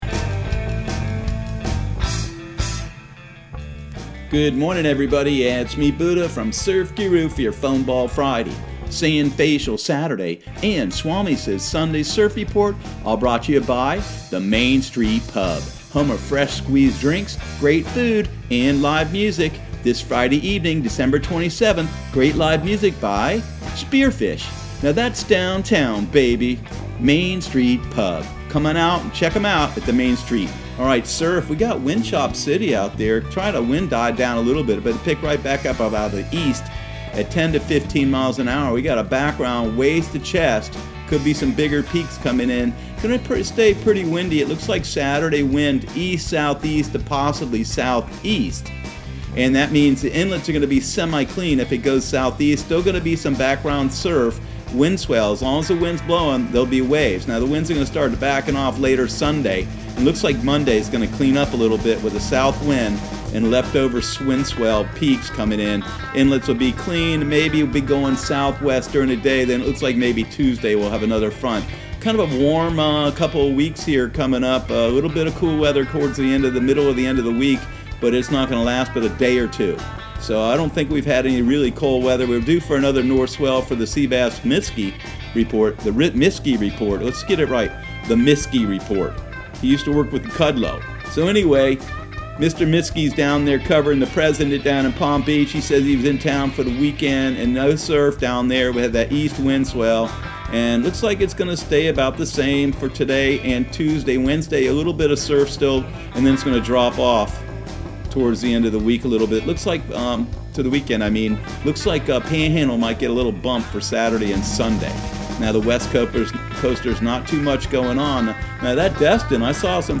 Surf Guru Surf Report and Forecast 12/27/2019 Audio surf report and surf forecast on December 27 for Central Florida and the Southeast.